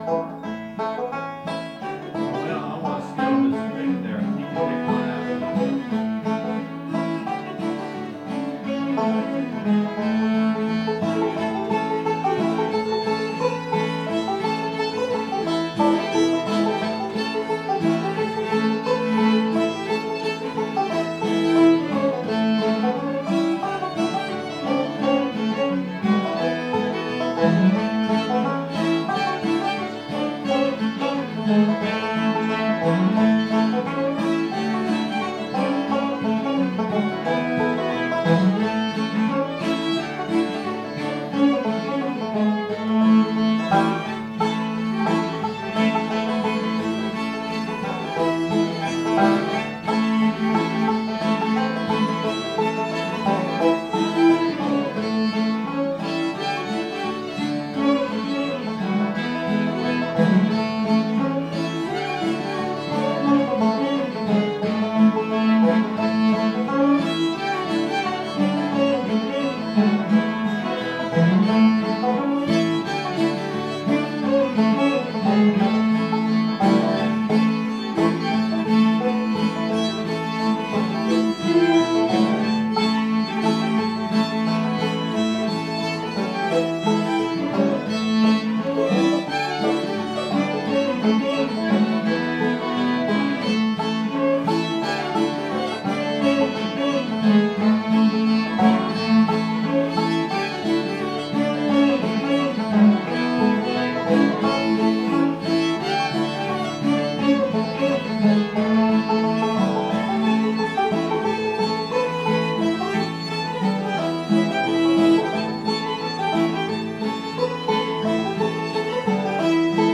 Here's a sample of every tune that has been recorded at the Pegram Jam
Most of the recordings should be clear enough to be useful for learning melodies and for practice accompaniment.